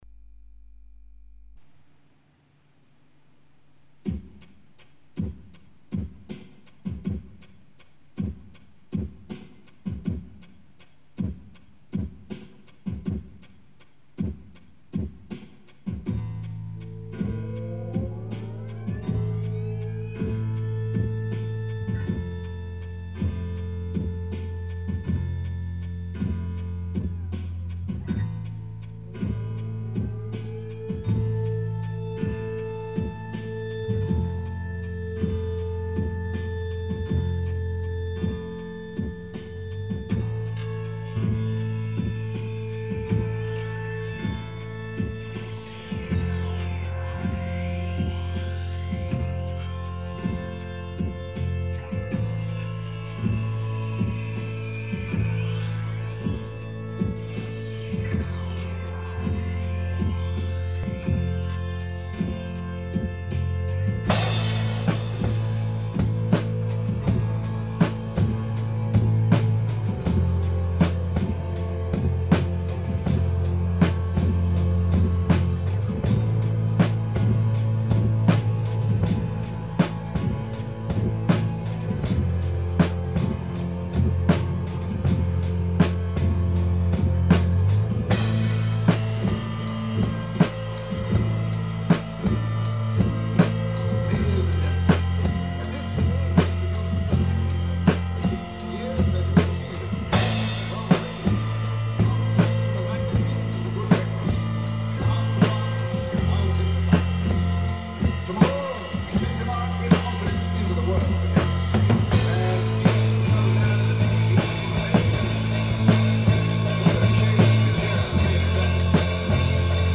- Groovebox - ·s